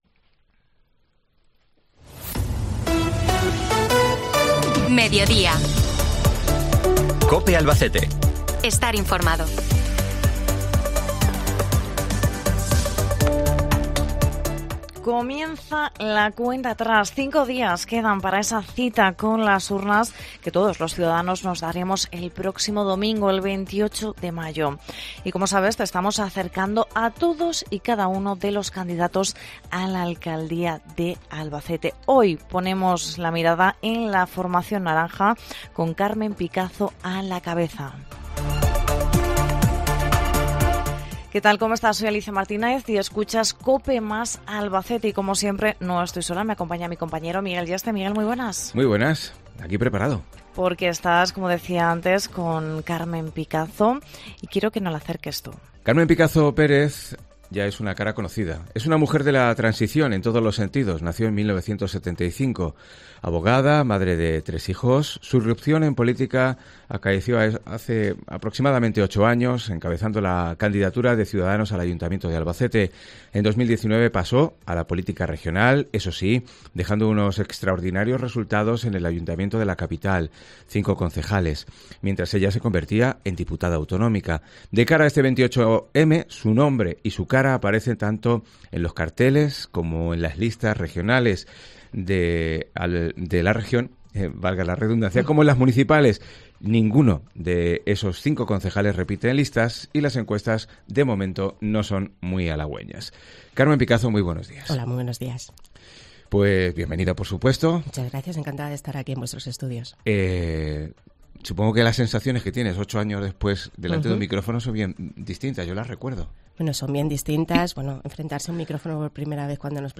Entrevista con Carmen Picazo- Ciudadanos